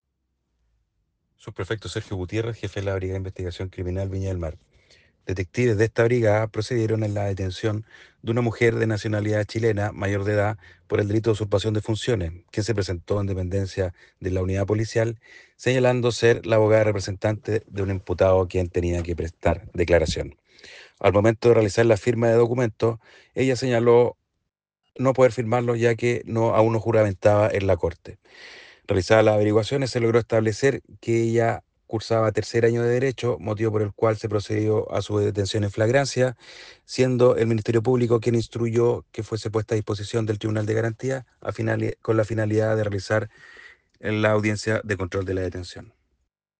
NOTA DE AUDIO de "PDI detiene a mujer por usurpación de funciones en Viña del Mar"